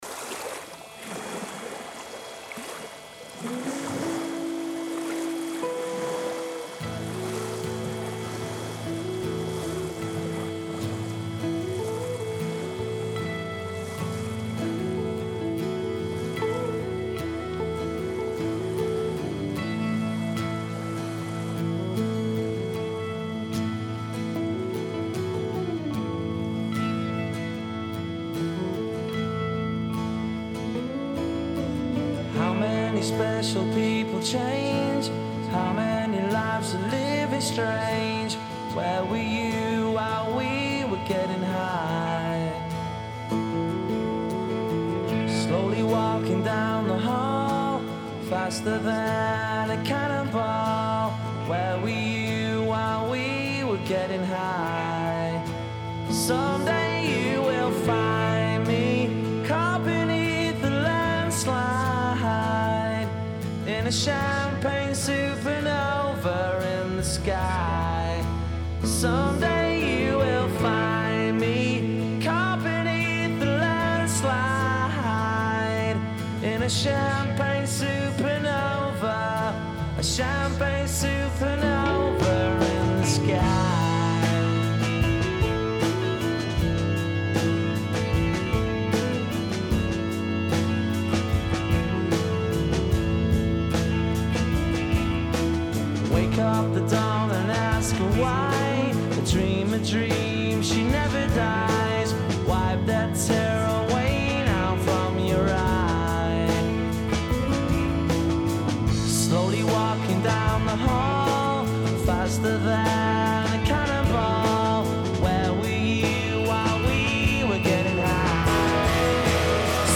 la voce squillante